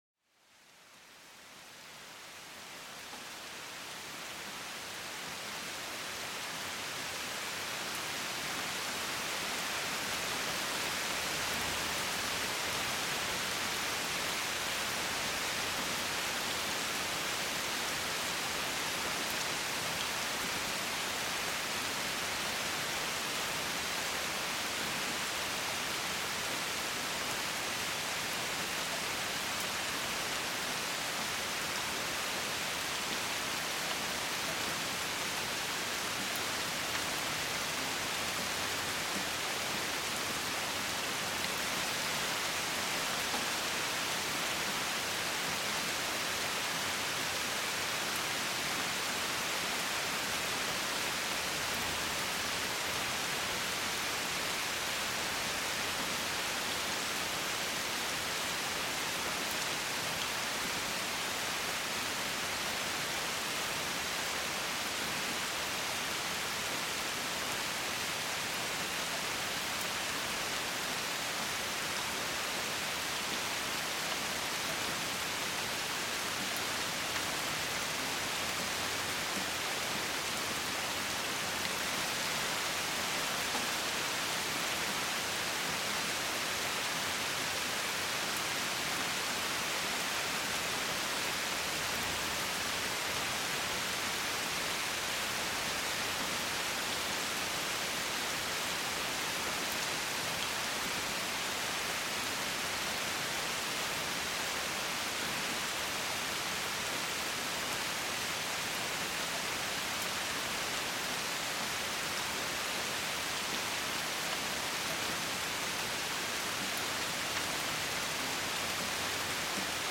Pluie intense pour un sommeil profond et apaisant
Découvrez la magie apaisante d'une pluie torrentielle qui vous berce jusqu'au sommeil. Ce son captivant est idéal pour se relaxer après une journée stressante et vous envelopper dans une ambiance naturelle.